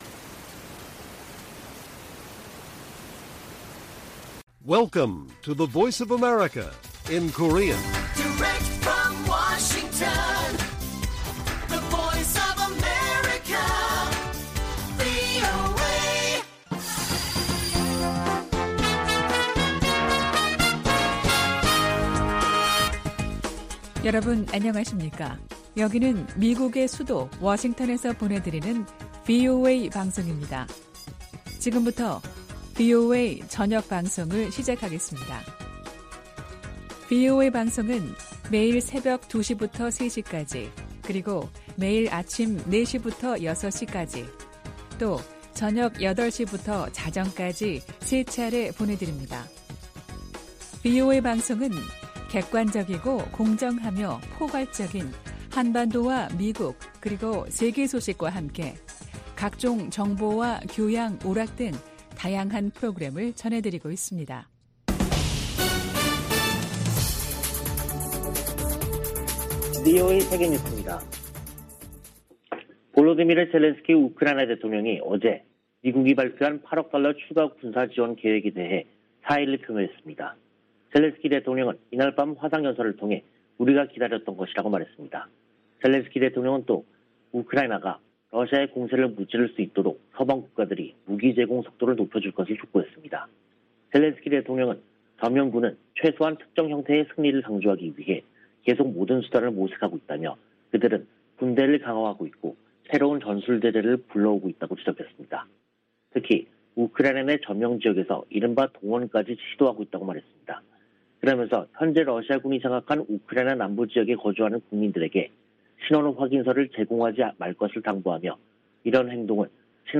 VOA 한국어 간판 뉴스 프로그램 '뉴스 투데이', 2022년 4월 22일 1부 방송입니다. 유럽연합(EU)이 북한의 잇단 미사일 발사에 대응해 북한 개인 8명과 기관 4곳을 독자제재 명단에 추가했습니다. 미 국무부는 북한의 도발에 계속 책임을 물리겠다고 경고하고, 북한이 대화 제안에 호응하지 않고 있다고 지적했습니다. 문재인 한국 대통령이 김정은 북한 국무위원장과 남북 정상선언의 의미를 긍정적으로 평가한 친서를 주고 받았습니다.